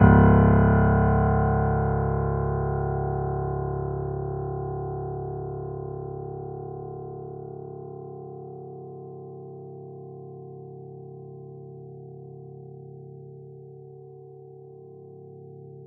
interactive-fretboard / samples / piano / Cs1.wav